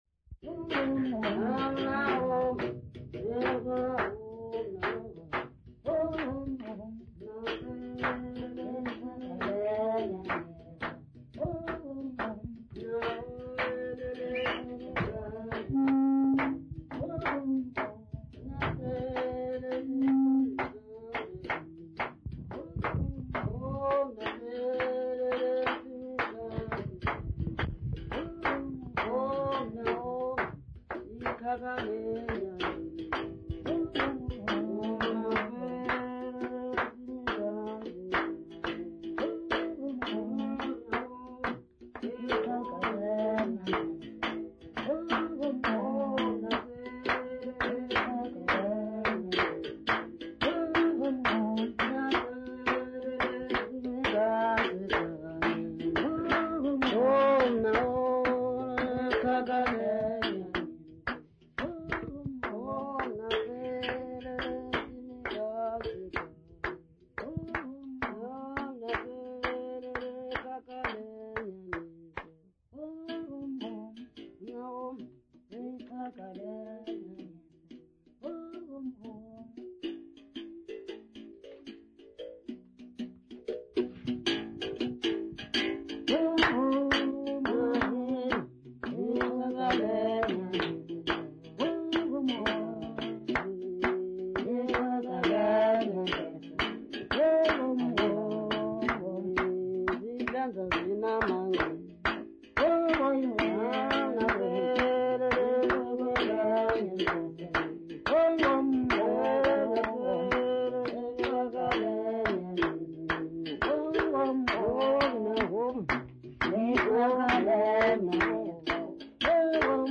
Xhosa women
Folk music South Africa
Stringed instrument music South Africa
field recordings
Traditional Xhosa song with Ntsikana's melody accompanied by clapping and the Uhadi.